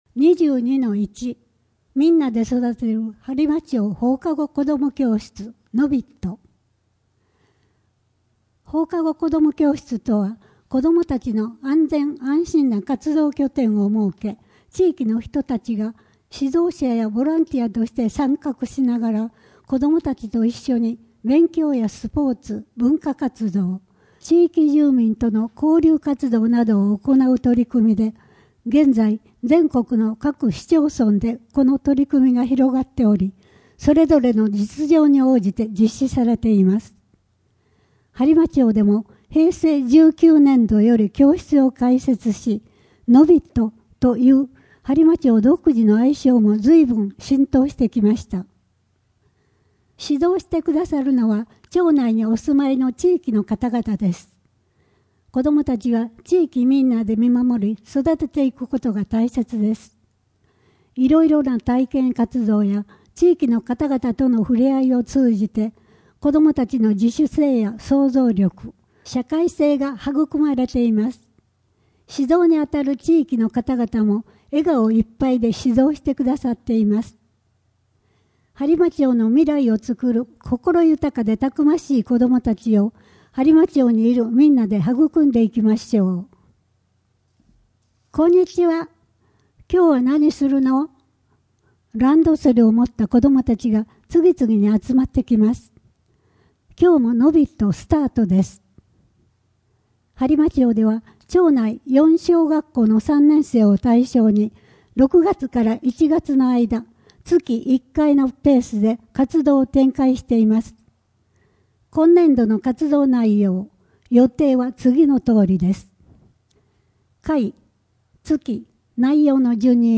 声の「広報はりま」9月号
声の「広報はりま」はボランティアグループ「のぎく」のご協力により作成されています。